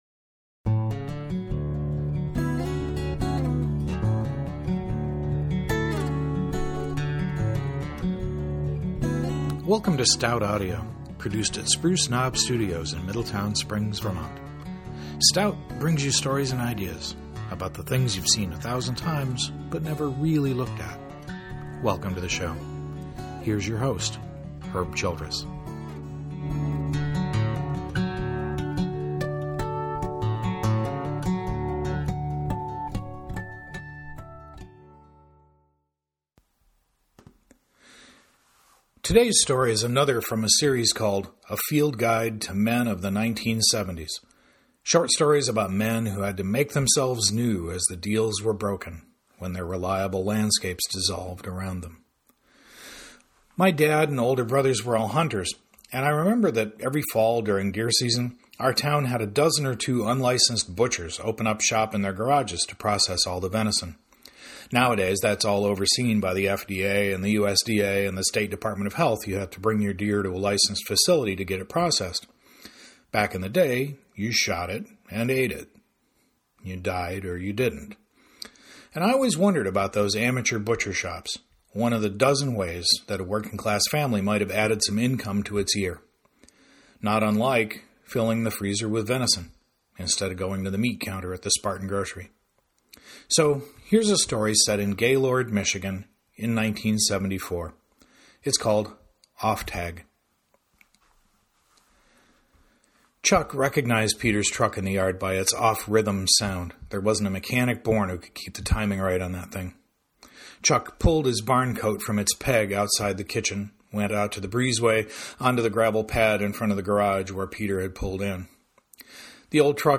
I’ve been working to learn the software and techniques of audio production for my short stories.
Nonetheless, “Off Tag” is now complete as a 30-minute audio short.